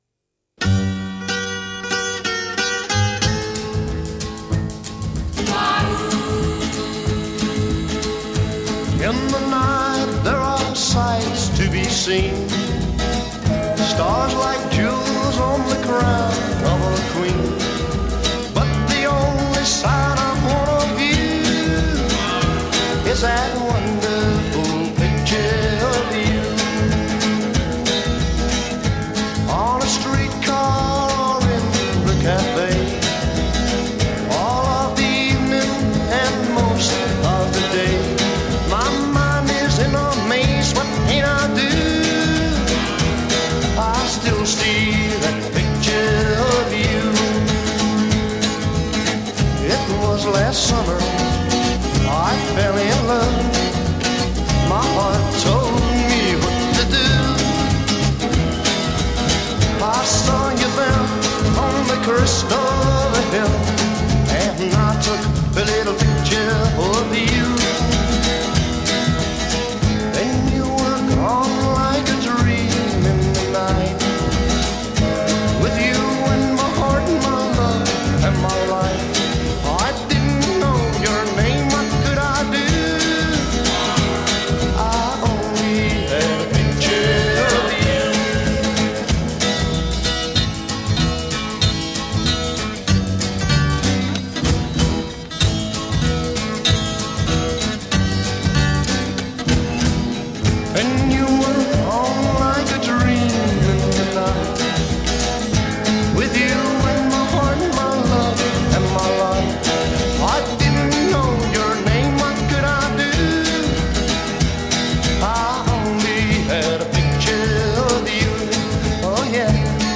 vocals and guitar
bass
drums
Location of Recording: Pye Recording Studios, London
intro 3   acoustic guitar introduction
sings with an American accent
chorus 4   shift in key area to minor c
verse* 4   guitar solo with breaks